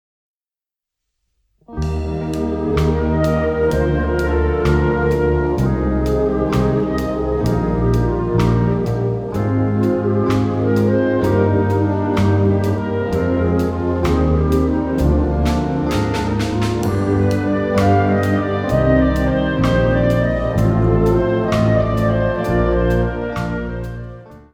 Besetzung Ha (Blasorchester); [ (optional); Rock-Band; ]